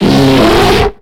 Cri de Pandarbare